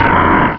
sovereignx/sound/direct_sound_samples/cries/grumpig.aif at master
grumpig.aif